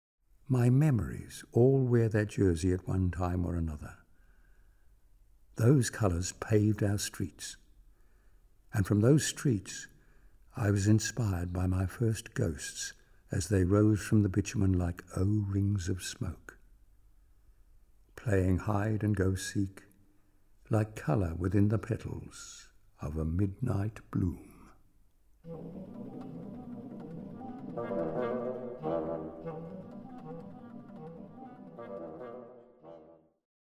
richly expressive woodwind palette